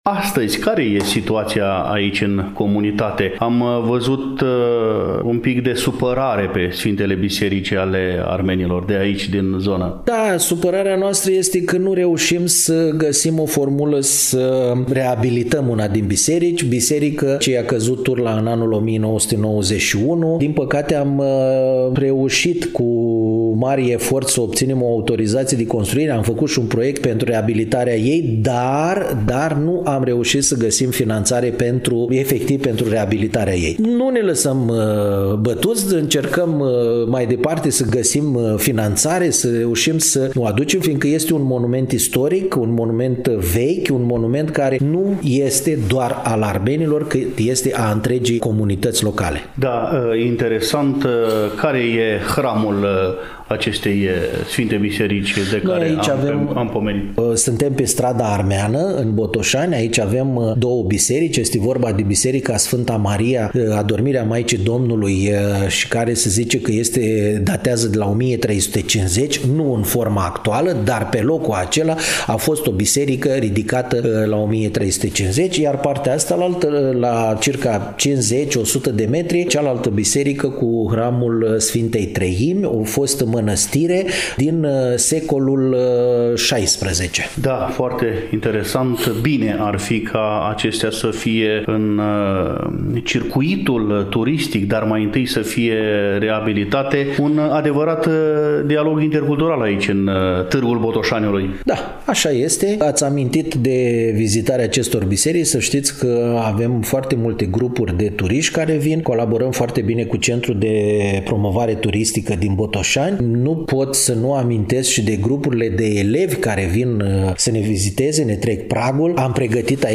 În ediția de astăzi a emisiunii Dialog intercultural, relatăm din Comunitatea Armenilor din Municipiul Botoșani, situată pe Strada Armeană, Numărul 20.